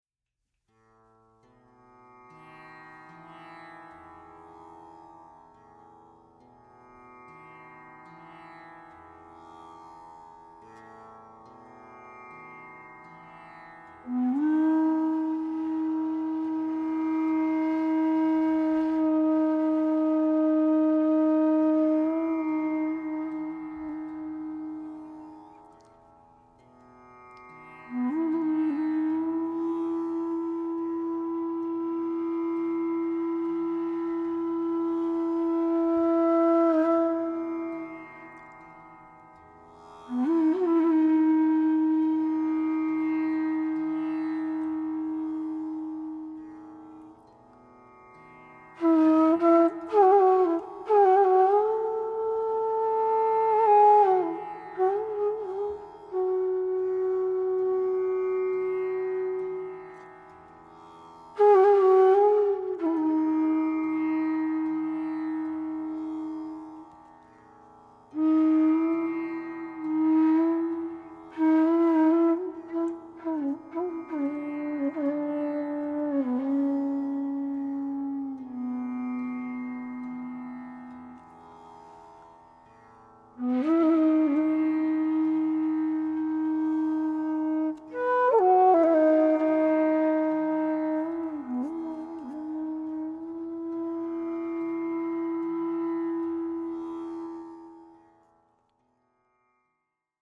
Contemplative bansuri melodies
tabla
Genre: North Indian Classical.
Matta Tal (9)   17:08